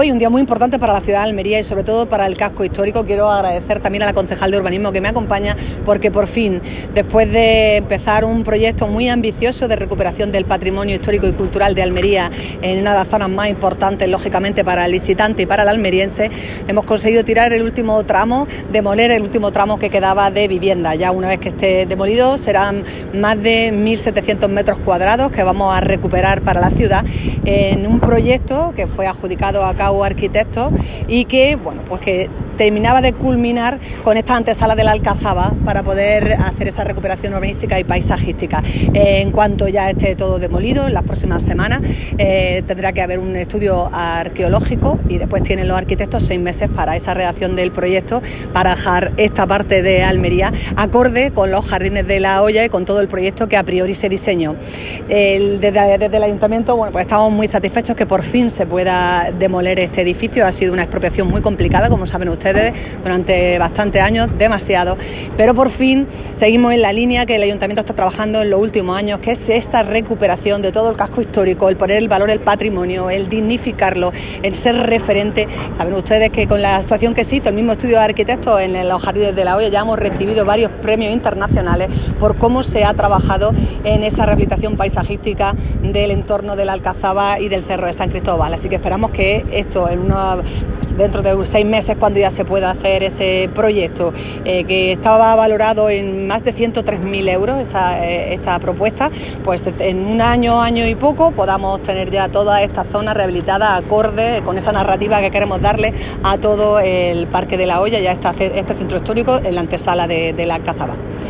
ALCALDESA-DEMOLICIONES-CALLE-HERCULES-Y-VINA.wav